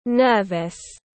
Nervous /ˈnɜː.vəs/